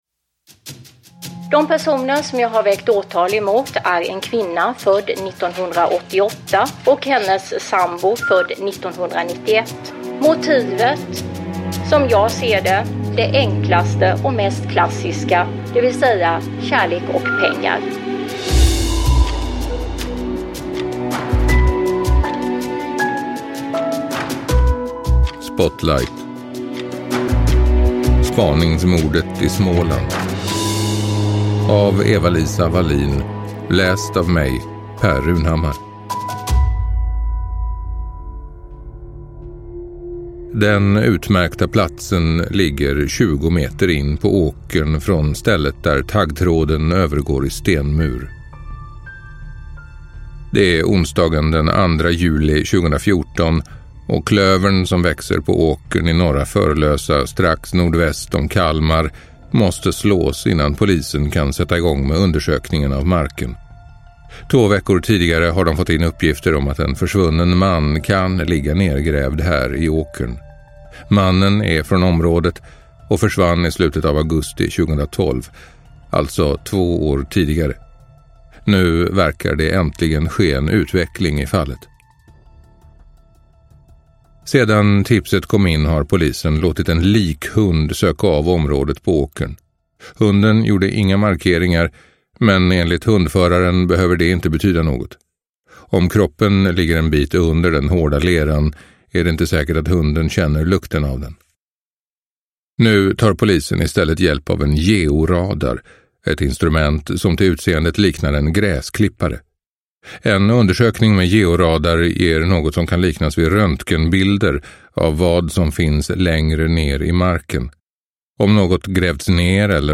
Spaningsmordet i Småland – Ljudbok – Laddas ner